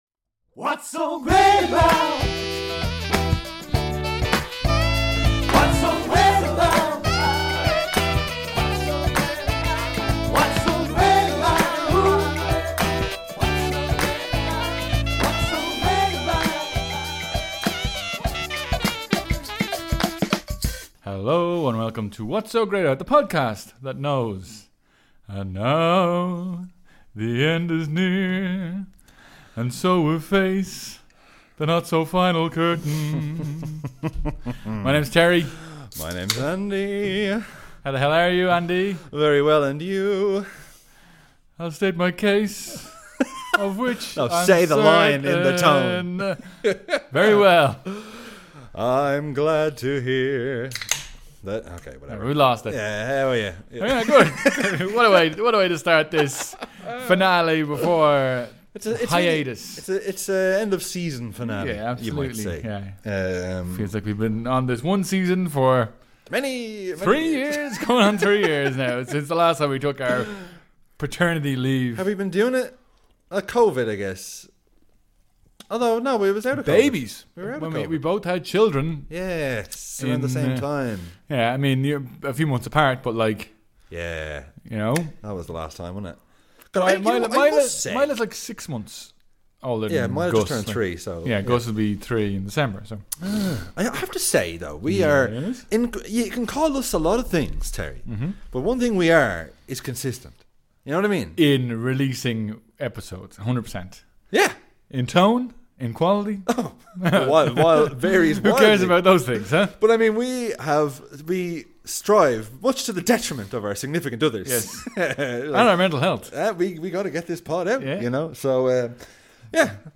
Its a good ol radio show!
We also play our favourite jams and try to come up with a backstory for fake footballers based on their name.